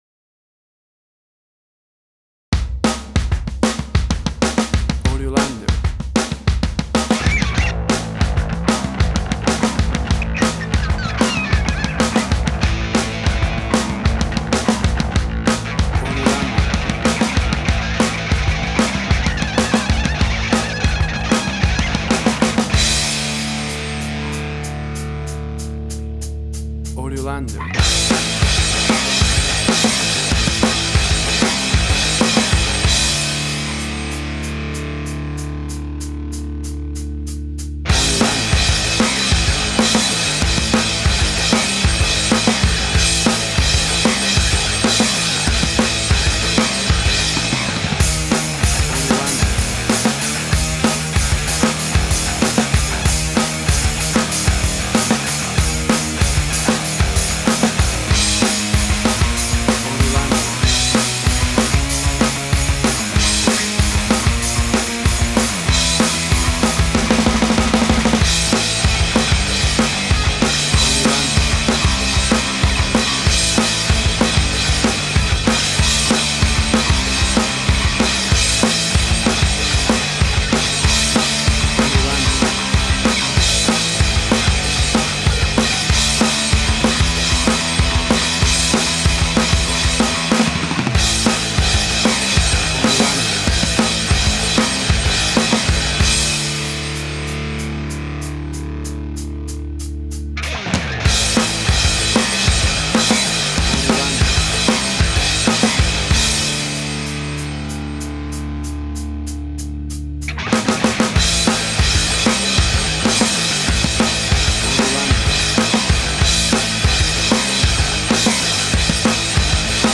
1990s style angry punk rock
Tempo (BPM): 190